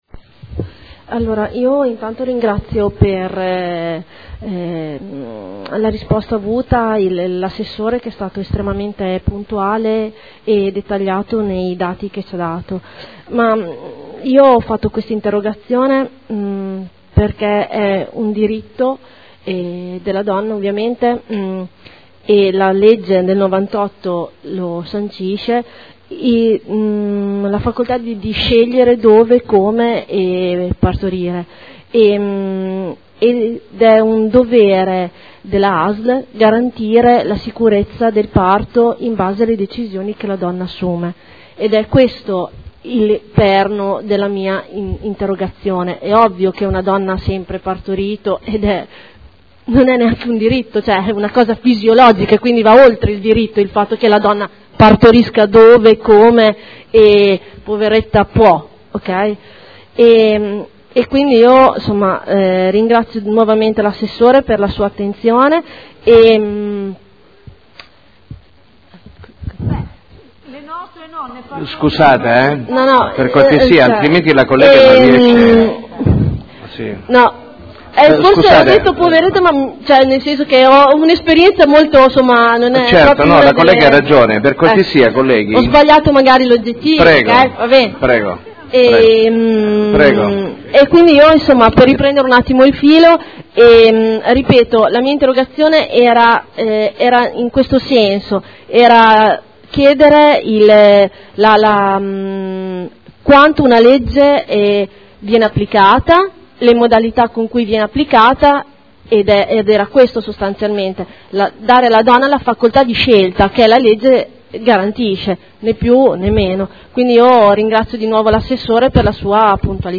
Claudia Codeluppi — Sito Audio Consiglio Comunale